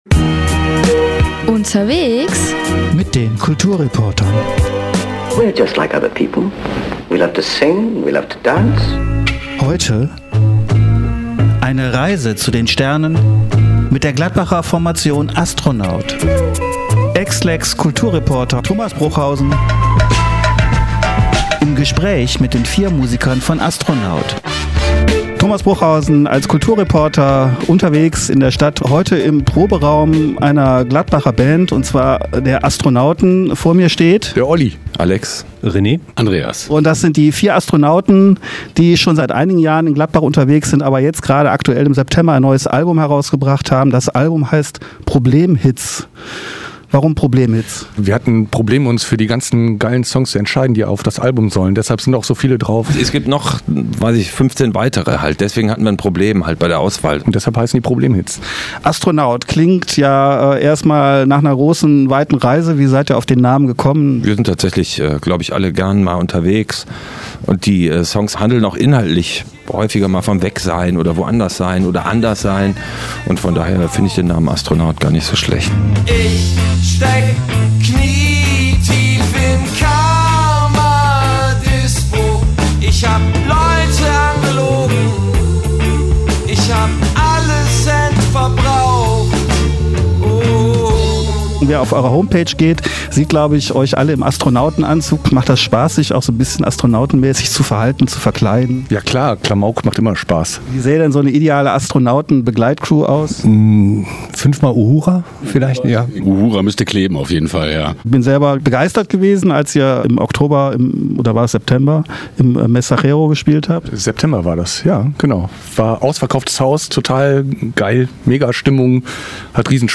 Interview-Astronaut-Komplett-TB_WEB.mp3